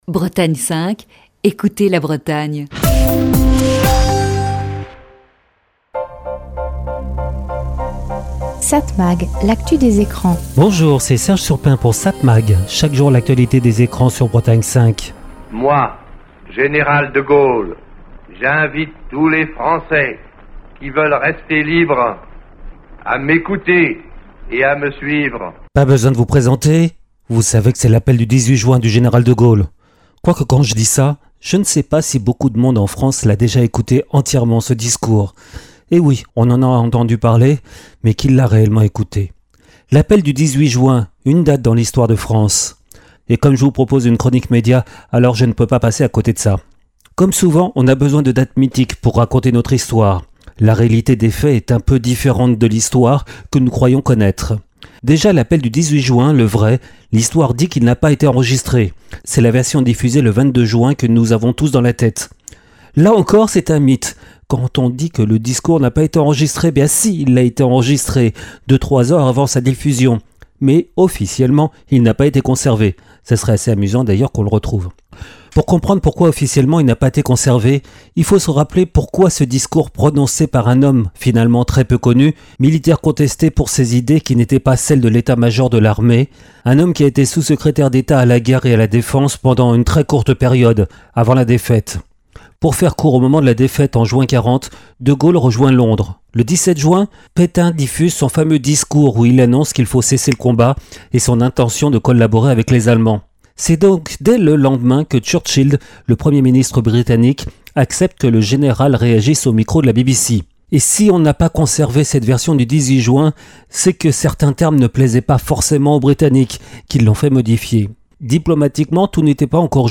Chronique du 18 juin 2020.